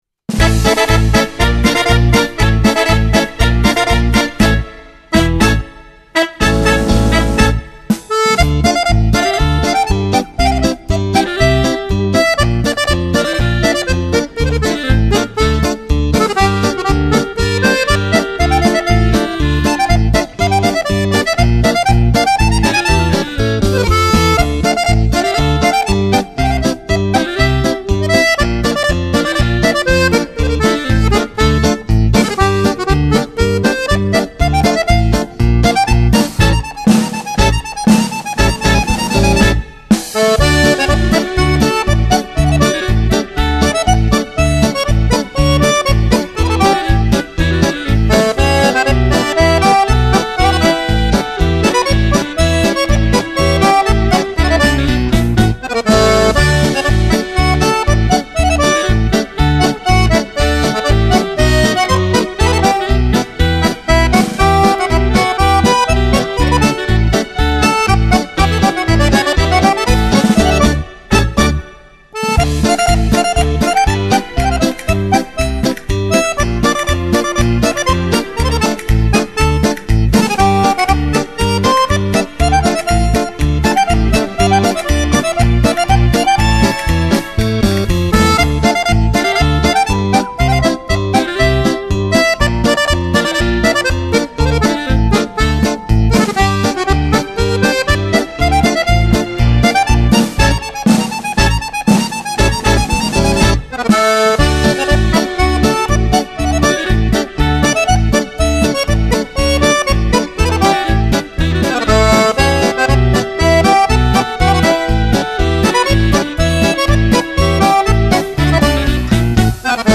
Genere: Paso doble